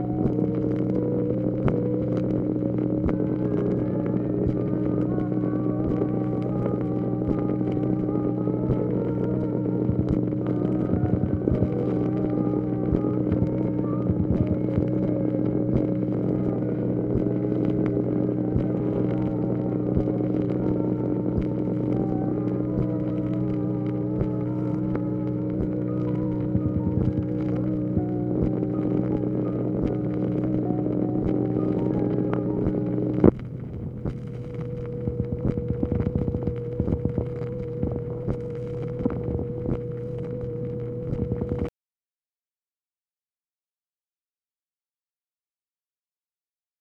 OFFICE NOISE, March 31, 1965
Secret White House Tapes | Lyndon B. Johnson Presidency